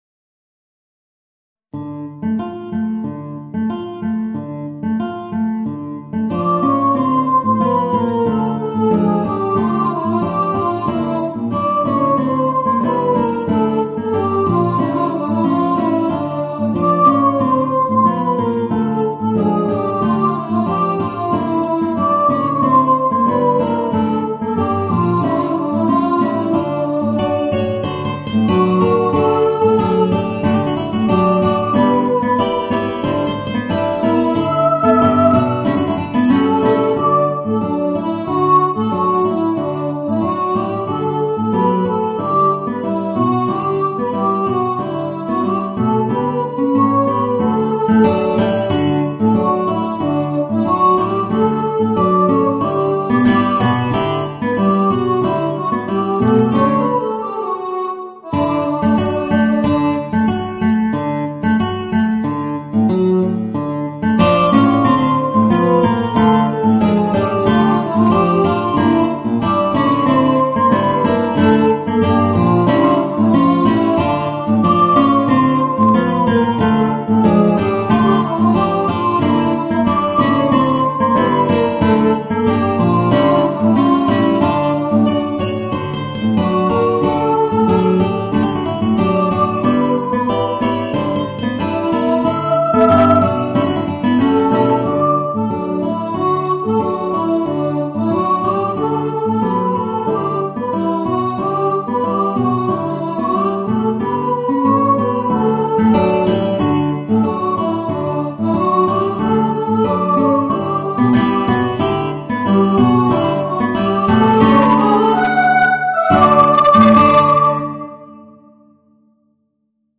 Voicing: Guitar and Mezzo-Soprano